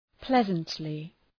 Προφορά
{‘plezəntlı}